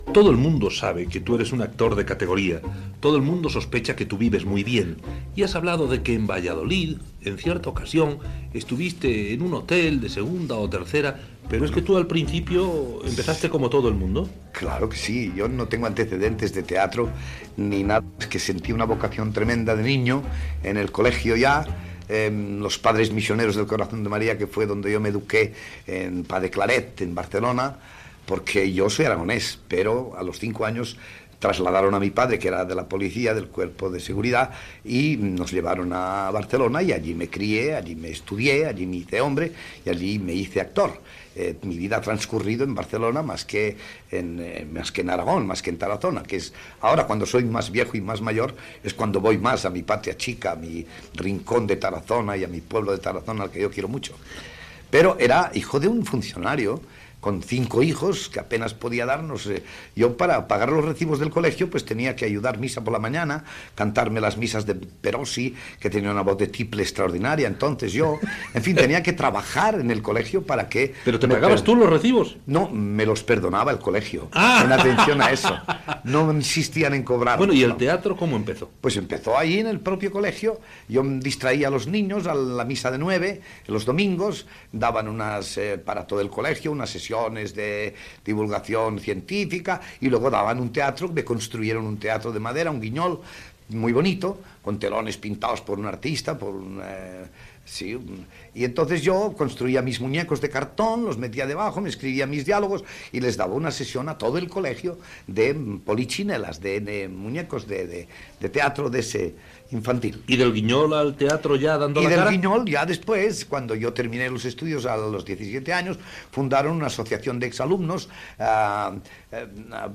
Entrevista a l'actor Paco Martínez Soria que recorda la seva infantesa, la seva escola a Barcelona i els seus inicis teatrals
Pécker, José Luis